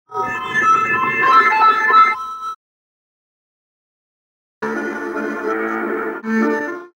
Но и, из тех, советских исполнений названного вальса "Память цветов", мне хотелось бы найти исполнение только с таким, ниже прилагаемым - для наглядности - звучанием вступления баяна (или всё-таки аккордеона?..) в чудом уцелевшем образце из пары мини-фрагментов, какие уж удалось спасти с давно клеенной-переклеенной плёнки, которая была «зажёвана» ещё в 90-ые, после его записи примерно в 1990 году с радио "Маяк" или с всесоюзного радио ("на Первой кнопке").
И я только немного искусственно программно «расставил» эту склейку - с интервалом, с каким эти куски должны были играться в оригинале (если память меня не подводит:))
recuerdo_cassette.mp3